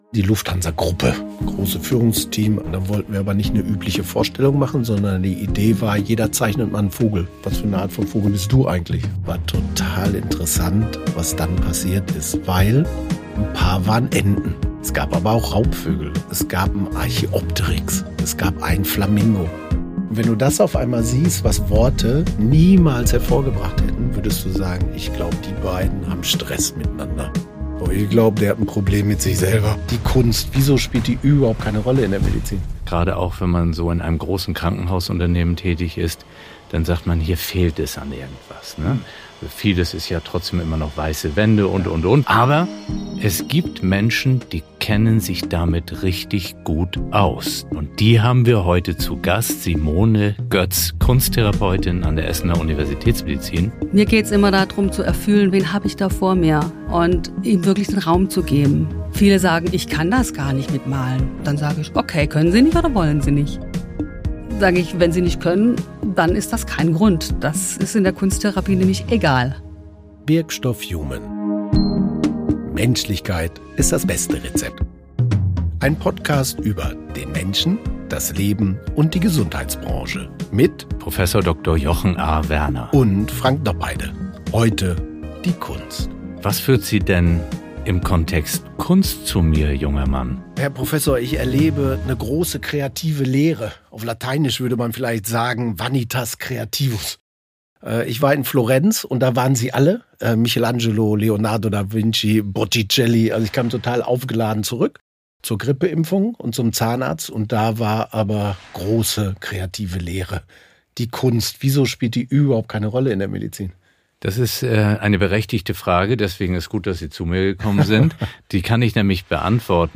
Ein Gespräch über Menschlichkeit, Intuition und die stille Kraft des Gestaltens. Und darüber, warum Heilung mehr ist als Medizin.